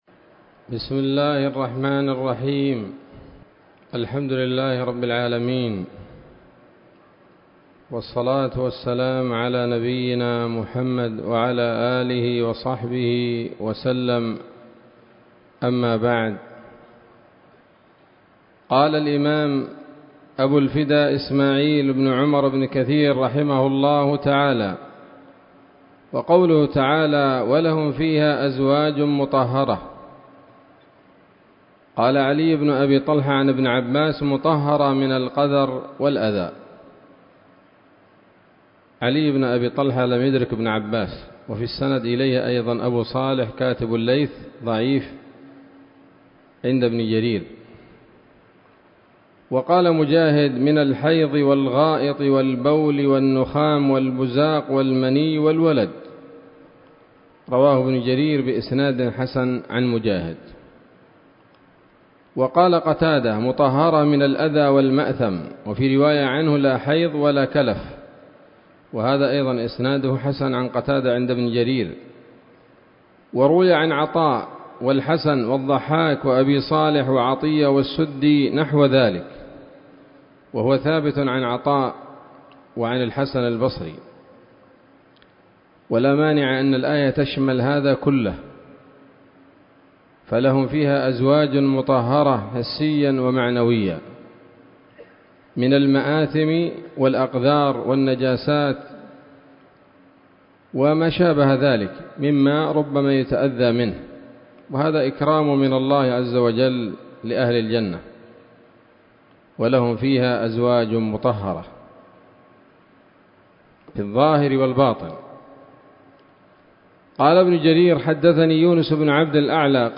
الدرس الثامن والعشرون من سورة البقرة من تفسير ابن كثير رحمه الله تعالى